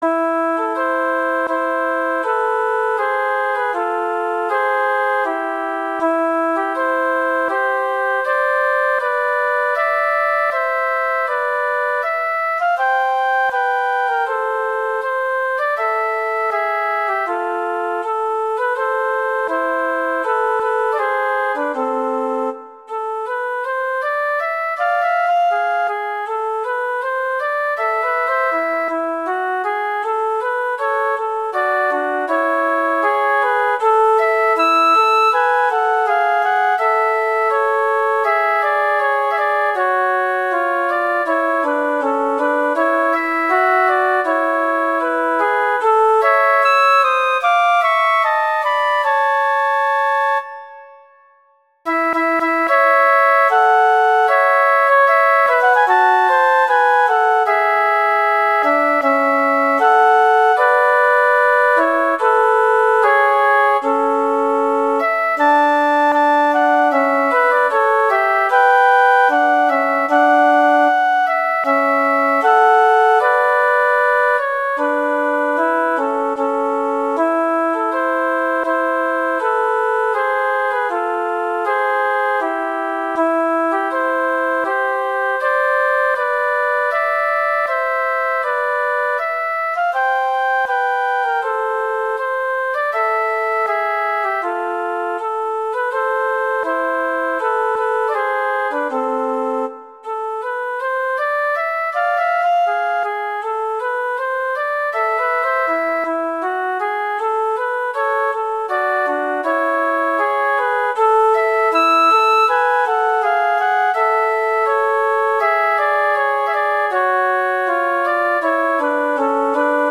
Instrumentation: two flutes